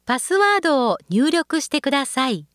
ホームページ作成で利用できる、さまざまな文章や単語を、プロナレーターがナレーション録音しています。
ナレーション： パスワードを入力してください ホームページ作成 京都 インターネットホームページ 活用 相談 京都市 ホームページ制作会社 京都 京都の音楽事務所 Surface とコラボレーション 有名デパートが、ファッションのジャンルで Surface とコラボレーションしている。